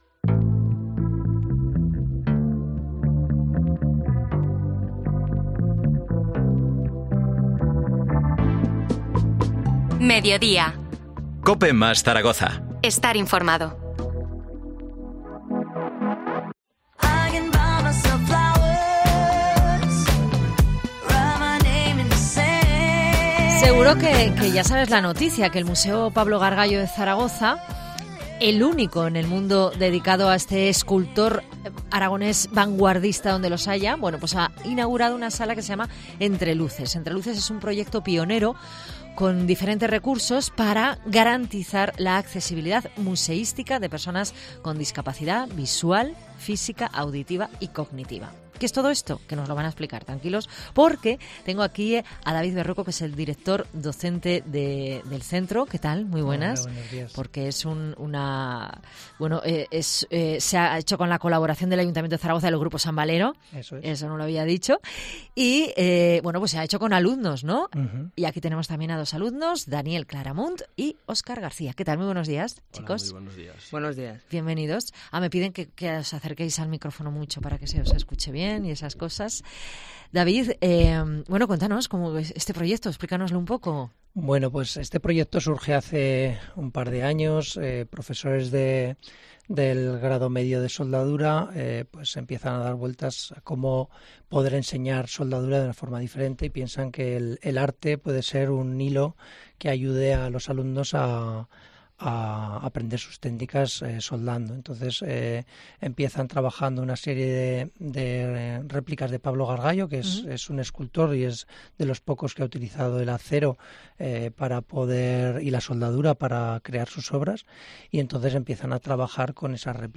Hablamos con algunos de los autores de las obras expuestas en la sala 'Entre Luces' de Zaragoza